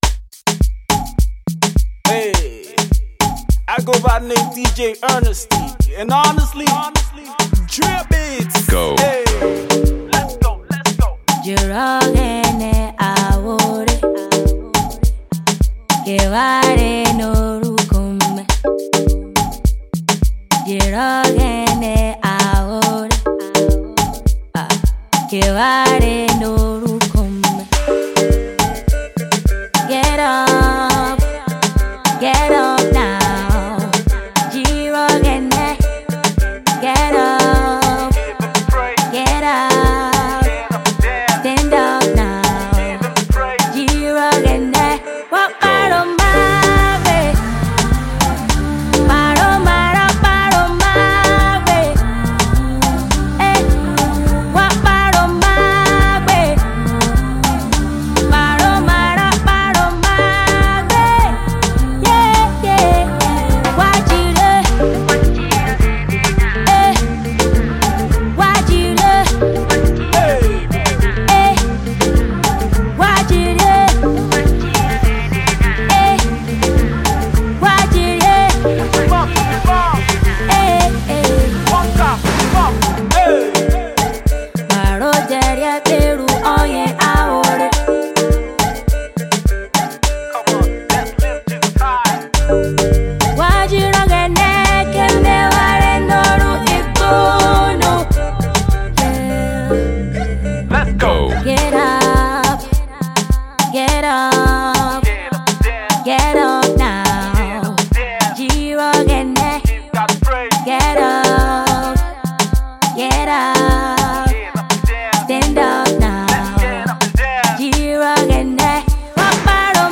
gospel
Gospel music